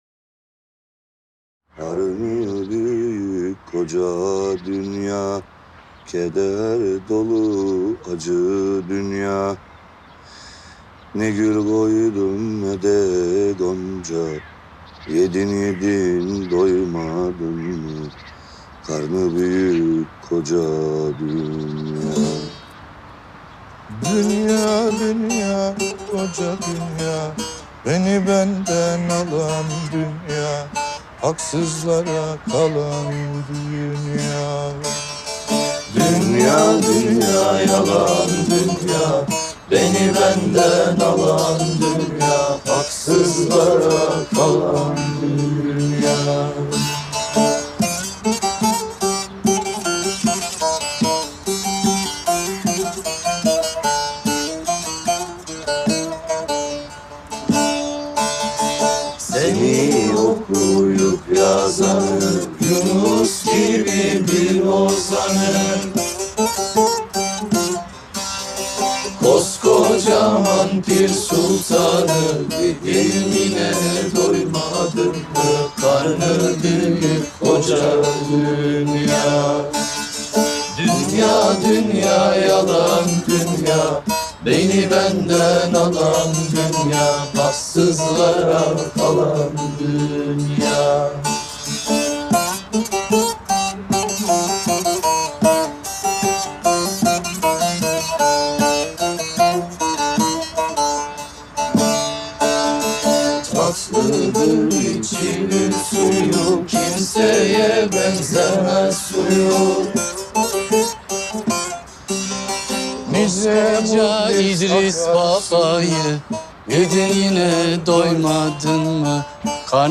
duygusal hüzünlü üzgün şarkı.